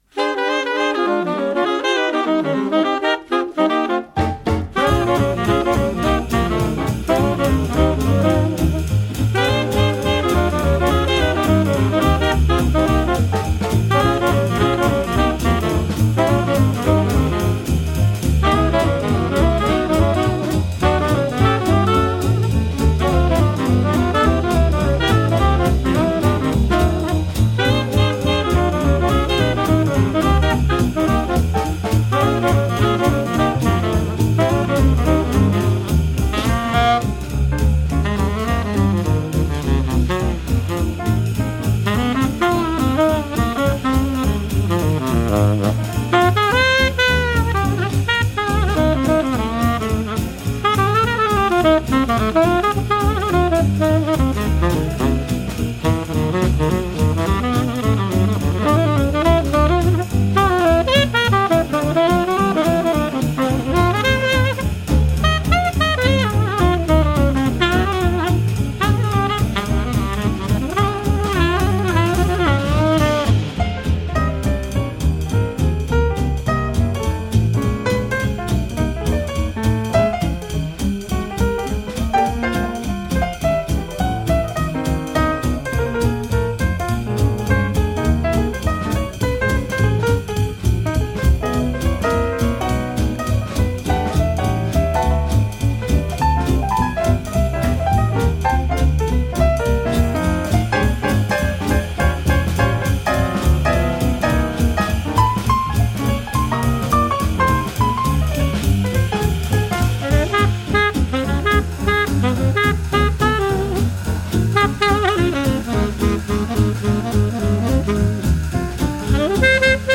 saxophones et clarinettes
piano
guitare
contrebasse
batterie